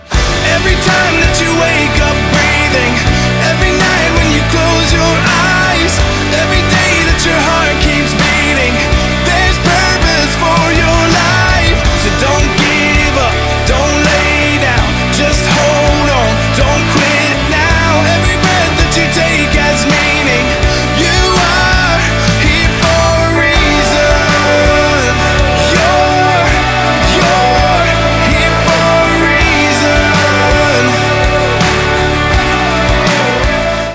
• Качество: 192, Stereo
мужской вокал
Рок песня о смысле жизни "Здесь по причине"